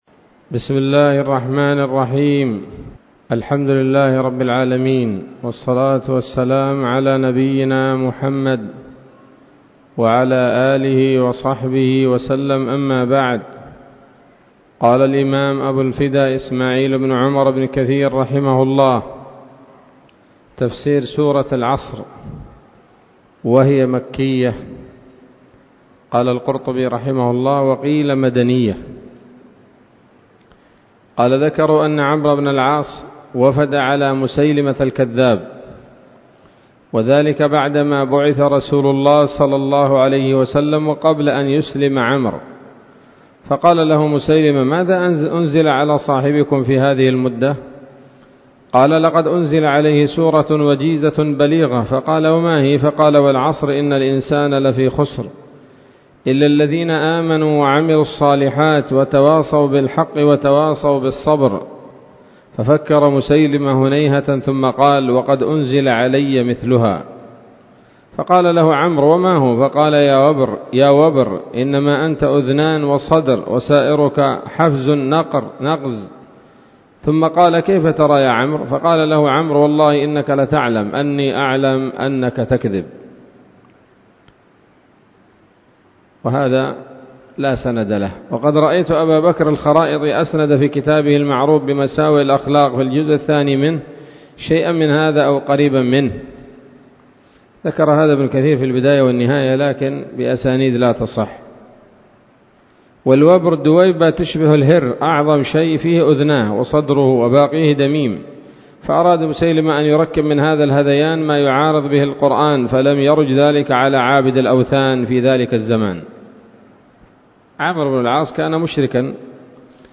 الدرس الأول والأخير من سورة العصر من تفسير ابن كثير رحمه الله تعالى